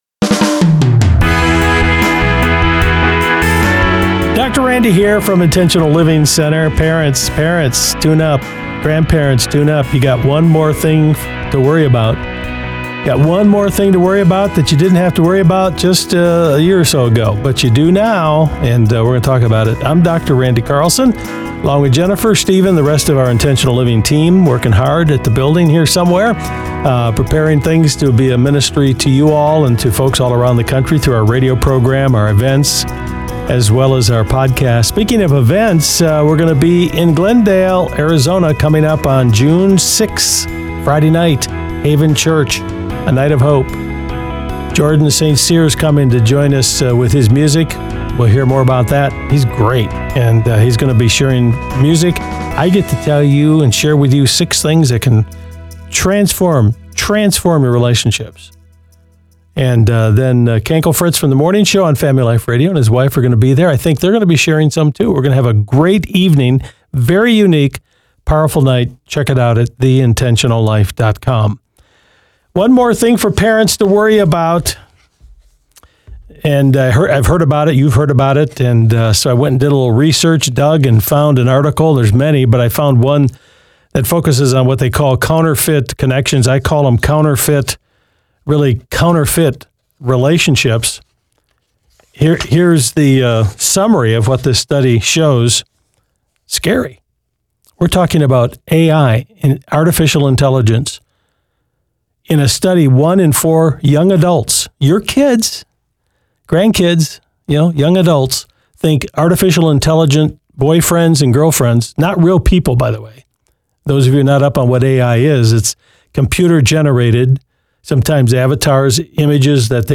Intentional Living is a nationally-syndicated program on more than 250 radio stations including Family Life Radio.